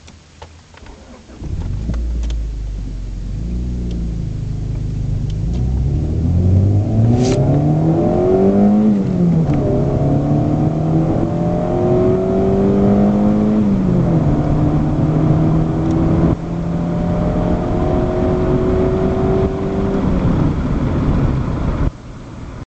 The cam activates the valves directly without the need of rockers; the result is a very strong engine but often critisised for noise.
Listen to my B20A6 being put through its paces with 7000rpm shifts in 1st and 2nd gears.
It has a stock exhaust.